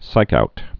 (sīkout)